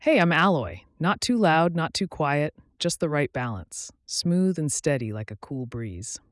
NavTalk 提供多种高质量的语音合成风格，您可以通过 voice 参数自由选择数字人音色：
中性权威，适合商务/科普